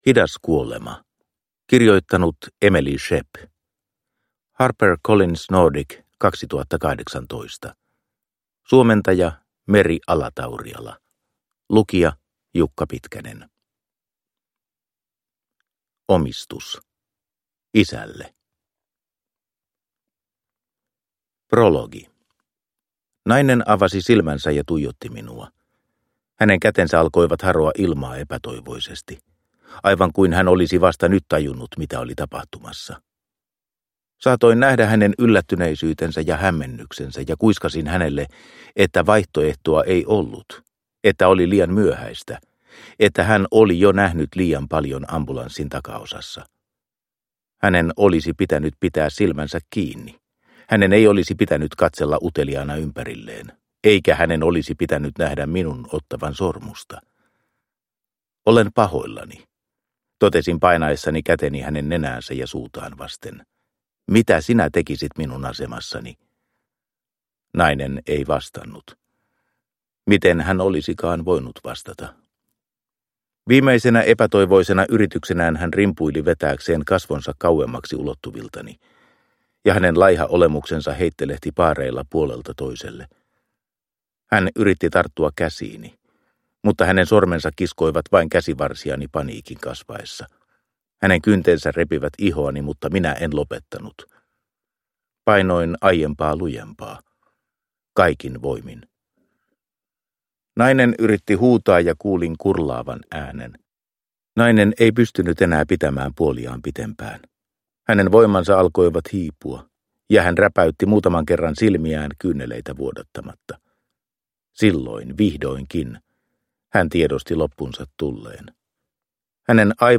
Hidas kuolema – Ljudbok – Laddas ner